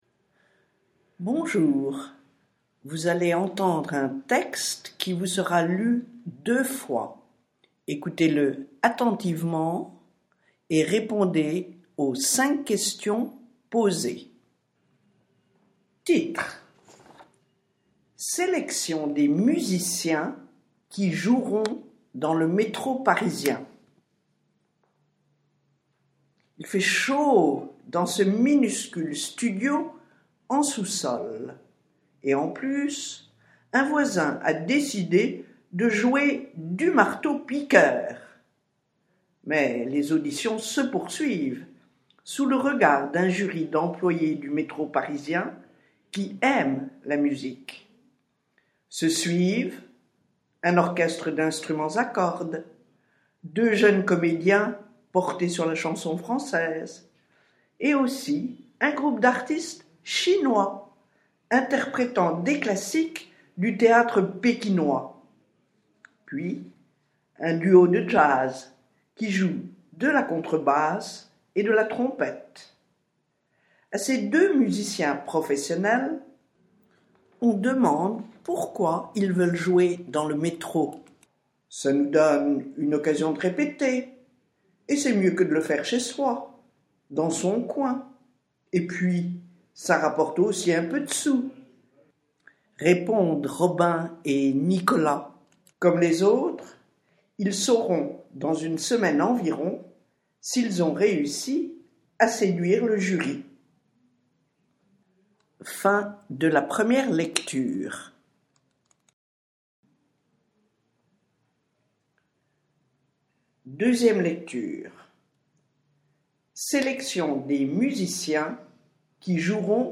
Premier exercice du sujet, texte à lire deux fois